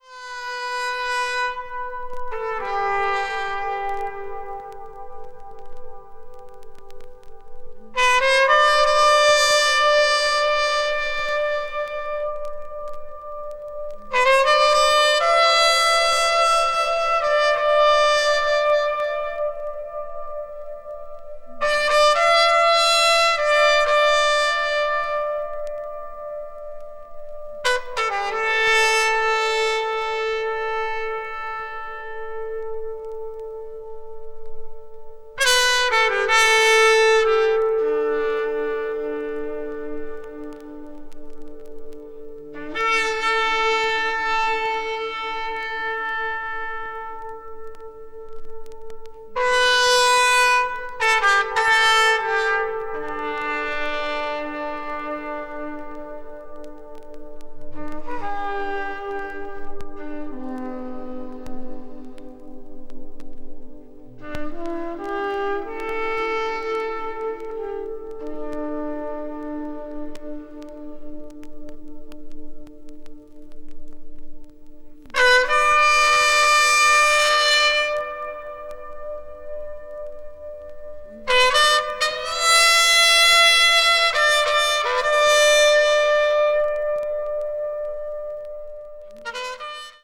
avant-jazz   contemporary jazz   free jazz   spiritual jazz